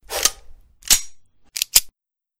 pistol Reload.wav